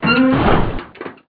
CRUNCH1.mp3